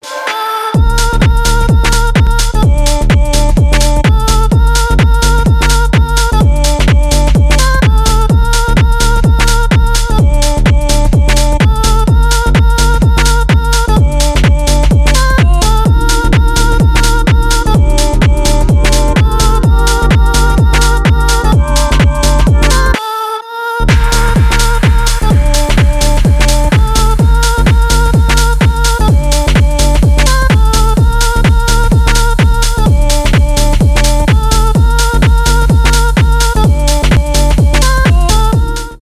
клубные
jackin house , без слов